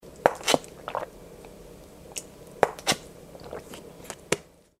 Drinking From Plastic Water Bottle